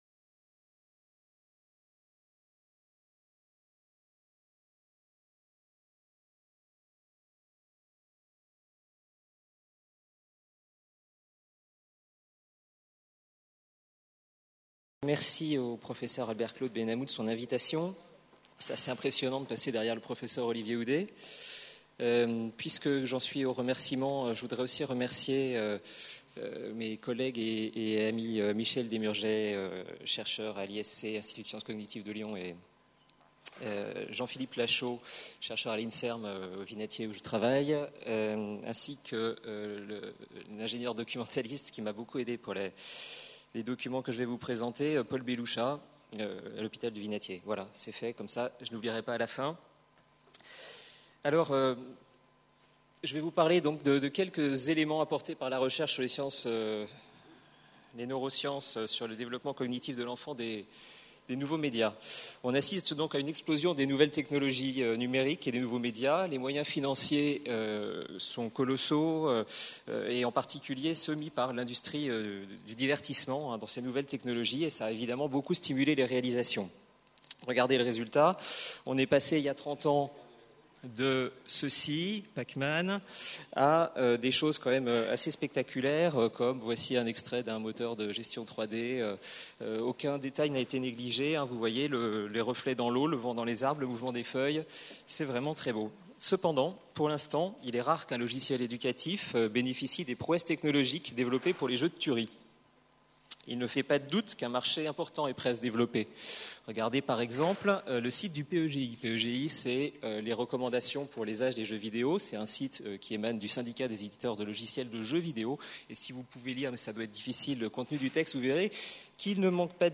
PREMIER SÉMINAIRE INTERNATIONAL SANKORÉ DE RECHERCHE UNIVERSITAIRE SUR LA PÉDAGOGIE NUMÉRIQUE Conférence-Débat : INSERM / SANKORE : ZOOM SUR LES NEUROSCIENCESQue disent les neurosciences sur l’Education numérique ?